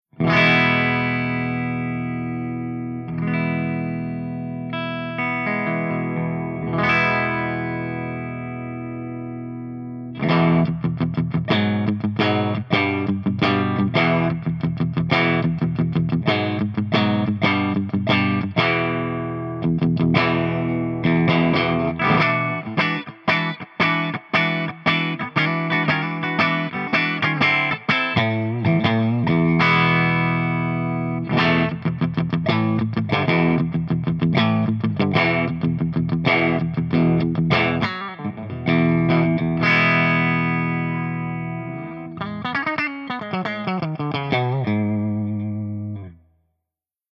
170_ENGLSP_CH1CLEANBRIGHT+REV_V30_P90